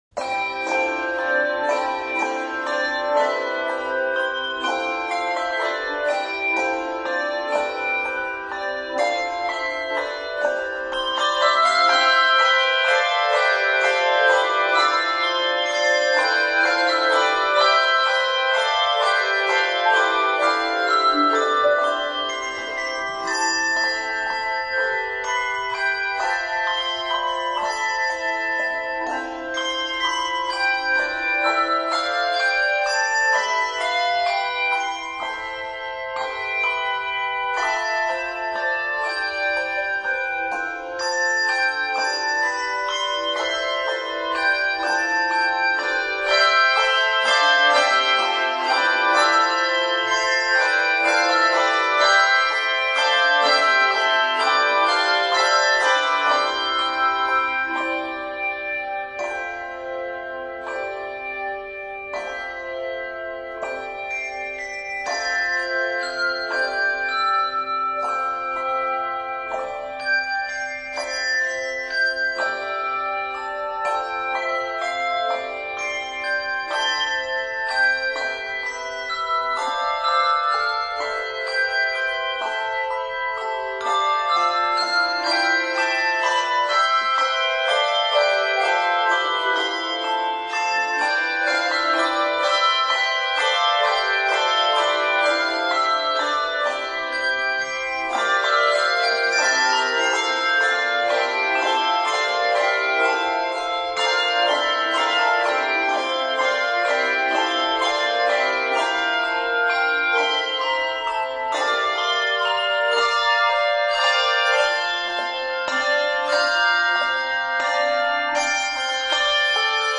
Various techniques are used.